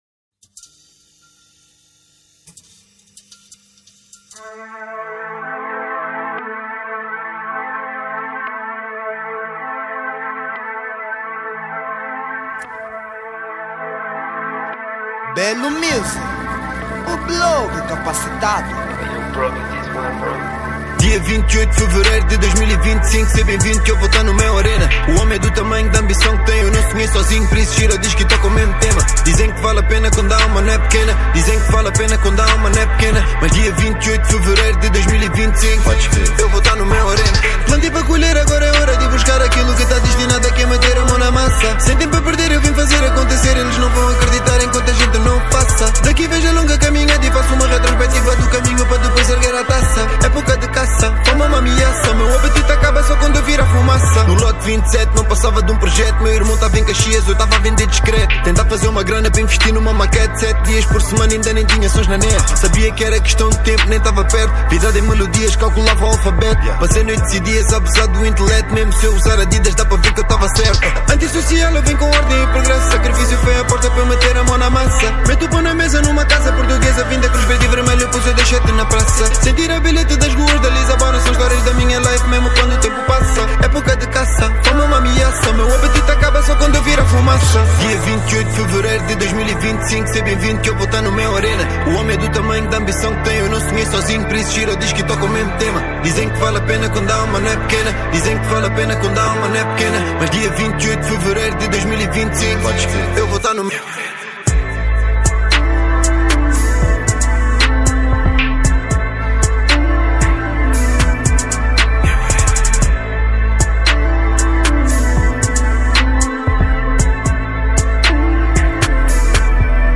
Género : Rap